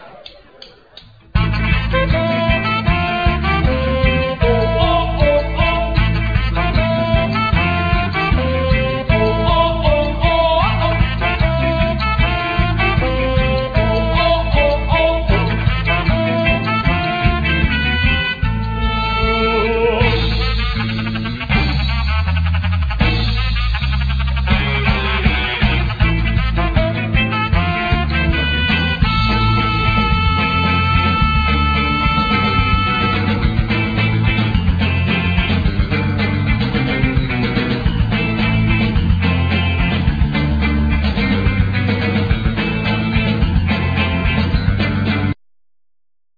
Vocals,Guitar,Keyboard
Saxophone,Vocals
Bass,Vocals
Drums